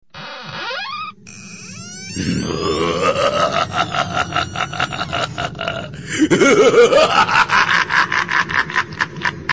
Смех монстра (Monster laugh)
Отличного качества, без посторонних шумов.